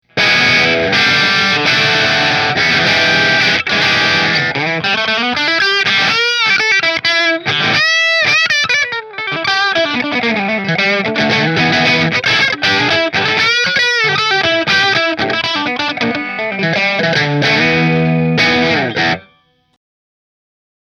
• HSS Kent Armstrong Pickup Configuration
Rahan Guitars RP Double Cutaway Figured Maple Position 5 Through Marshall